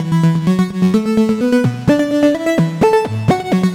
Index of /musicradar/french-house-chillout-samples/128bpm/Instruments
FHC_Arp B_128-E.wav